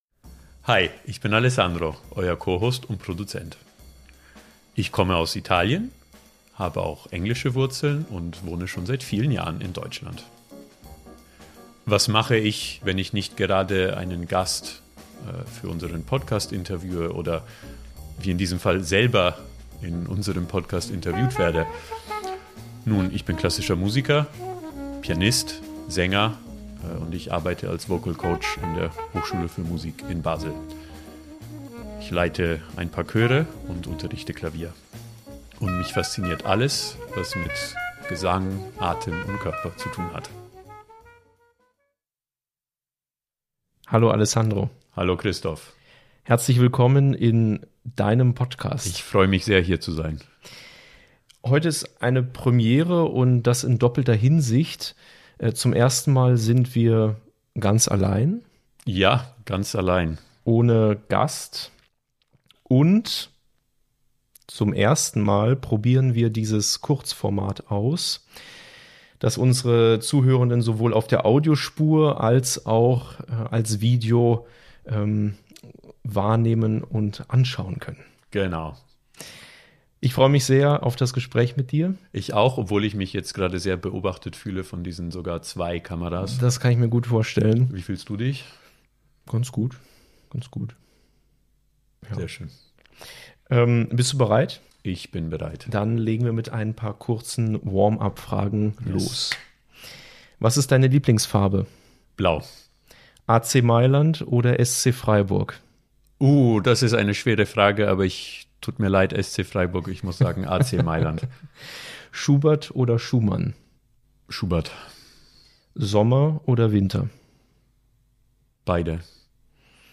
Das Interview ist als Video auf YouTube zu sehen.